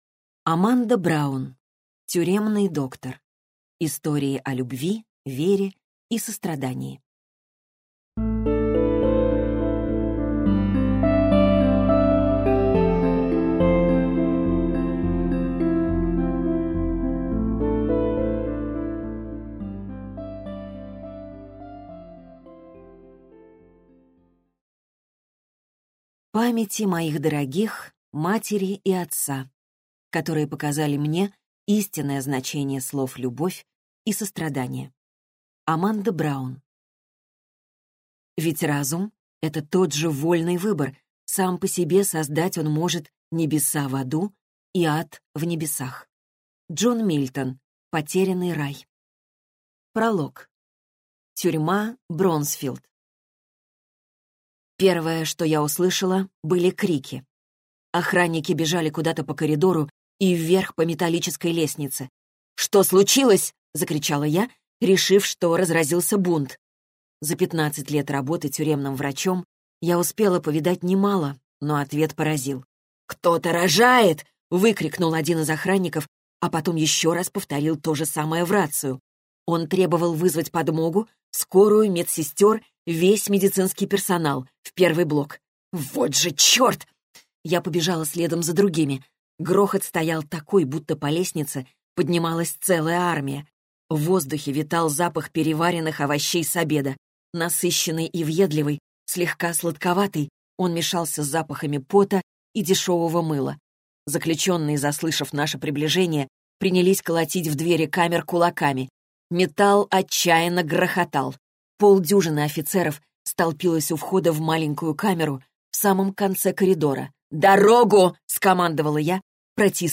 Аудиокнига Тюремный доктор. Истории о любви, вере и сострадании | Библиотека аудиокниг